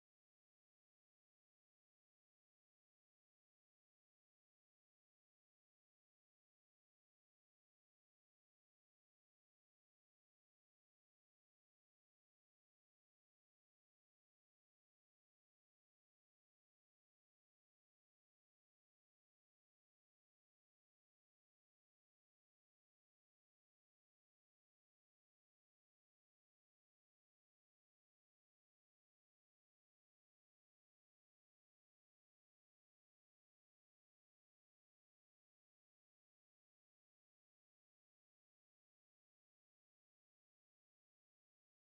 RobotWhaleSoundEffect.wav